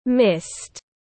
Sương muối tiếng anh gọi là mist, phiên âm tiếng anh đọc là /mɪst/.
Mist /mɪst/